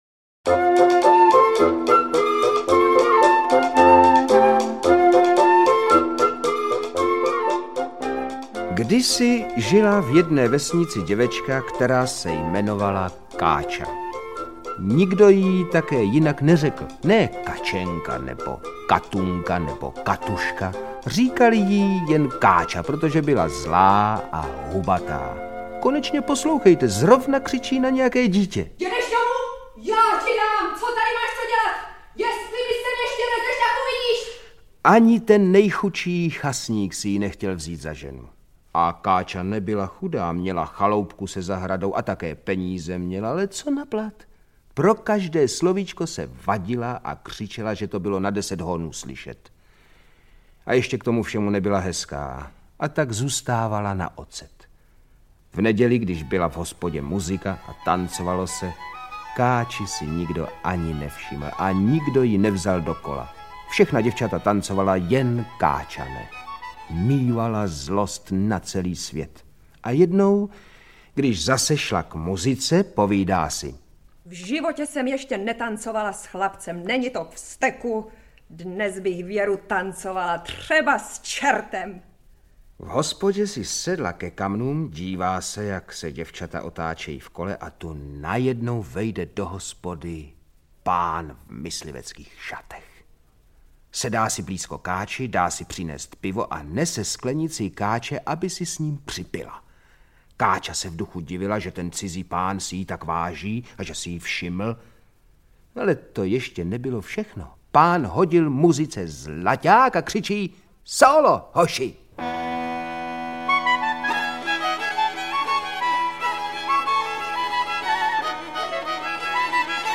Z díla Boženy Němcové známe pohádky Čert a Káča, Čertův švagr, z lidových pramenů pak Švec a čert a Čertův mlýn, vše namluveno řadou oblíbených herců, některých již legendárních (František Filipovský, Vladimír Brabec). S čerty nejsou žerty čte Ondřej Vetchý, jeden z hrdinů stejnojmenného filmu. Čertoviny, zpracované podle posledního pohádkového filmu Zdeňka Trošky, pak vypráví jeden ze dvou hlavních představitelů Jakub Prachař. Většinu těchto pohádek spojuje hlavní motiv - čerti jsou sice ve službách zla, ale ve skutečnosti pomáhají dobru odhalit podvodníky, šejdíře, ziskuchtivce, vrahy a zloděje.